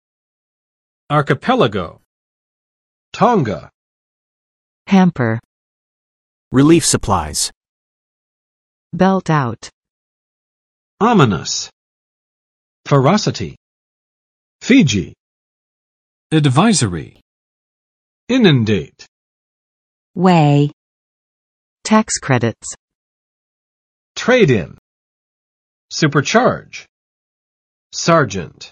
[͵ɑrkəˋpɛlə͵go] n. 群岛，列岛